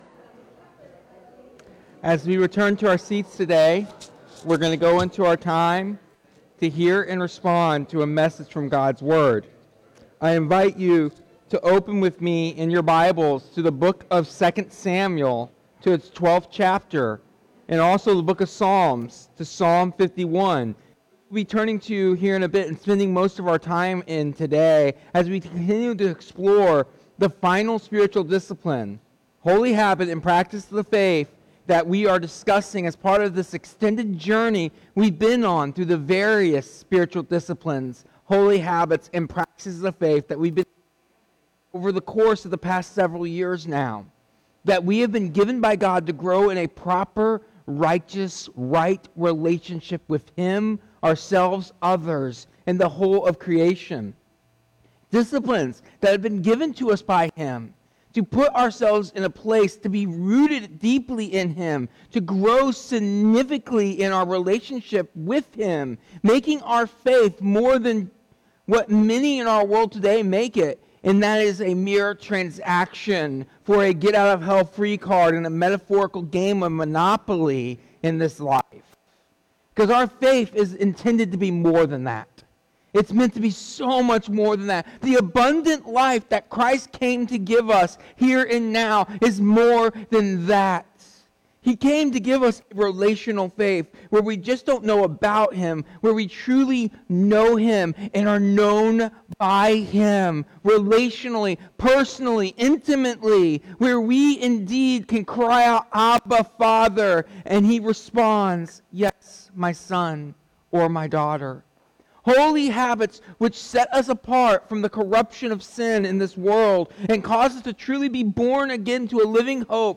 This sermon concludes a long series on Spiritual Disciplines, Holy Habits, and Practices of Faith, finishing the discipline of confession. It emphasizes that faith is not transactional but relational, meant to draw believers into deeper intimacy with God and into the transformation of life.